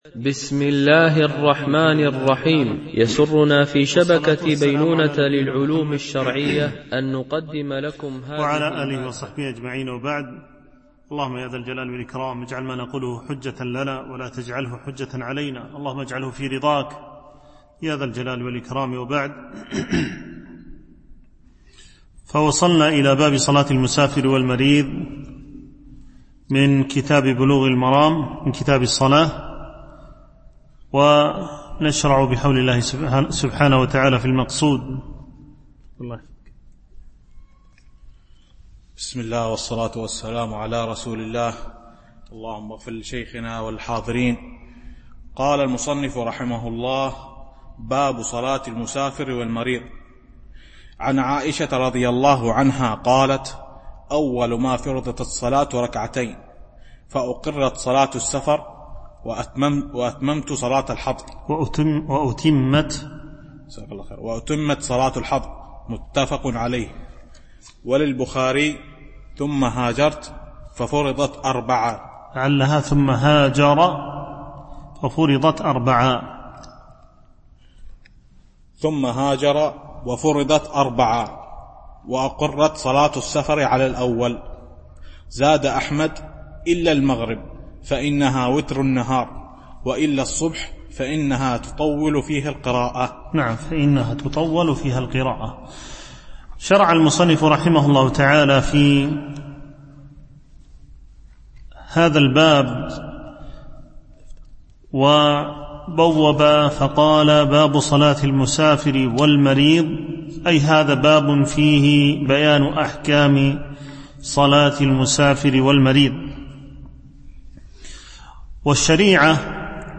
شرح كتاب بلوغ المرام من أدلة الأحكام - الدرس 57 (كتاب الصلاة ، الحديث 410 -421 )